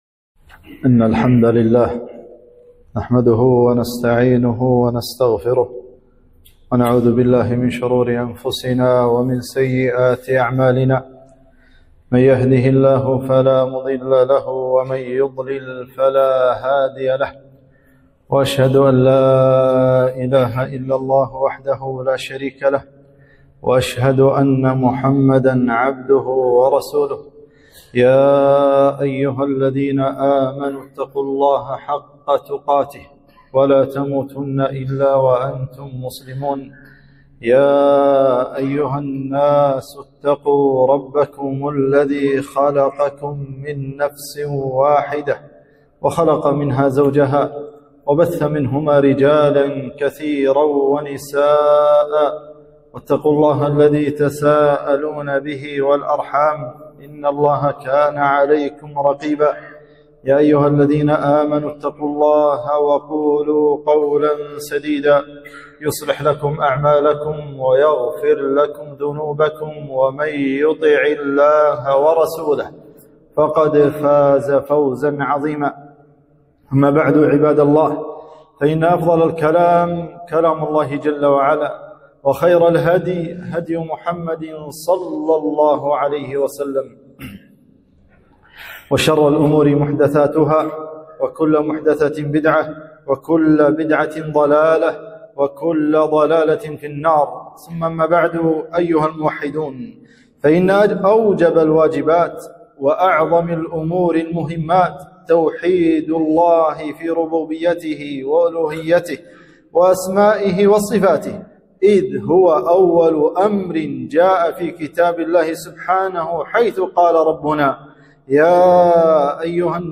خطبة الشرك الأصغر وصوره